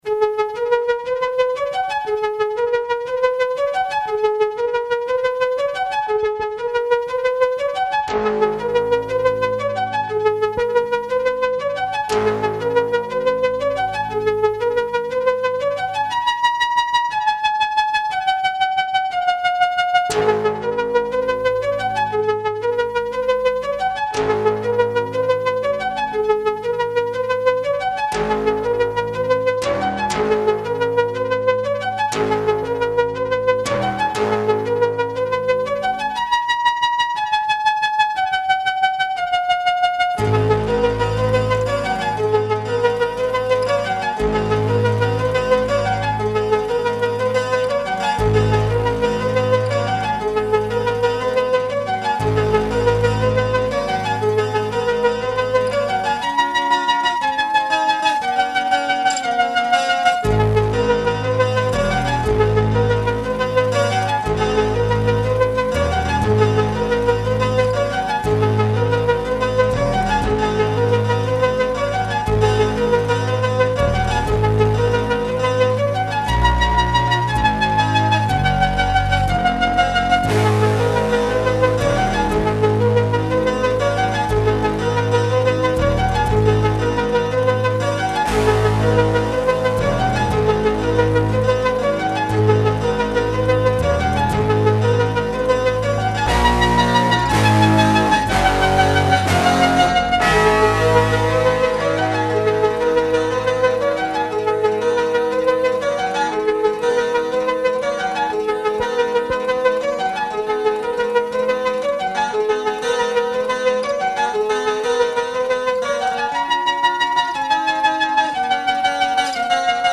guitars, basses, keyboards, programming